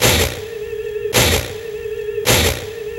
80BPM RAD6-R.wav